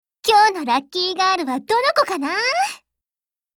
Cv-30802_warcry.mp3